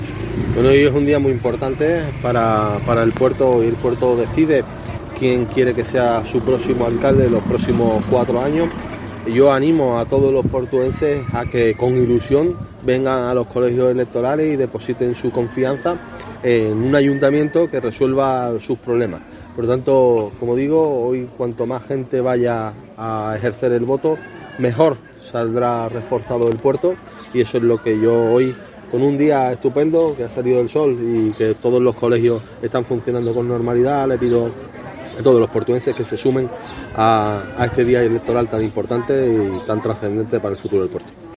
El candidato a la reelección para continuar al frente de la alcaldía portuense ha votado en el colegio Luis de Marillac